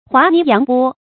滑泥揚波 注音： ㄏㄨㄚˊ ㄋㄧˊ ㄧㄤˊ ㄅㄛ 讀音讀法： 意思解釋： 謂同流合污，隨俗浮沉。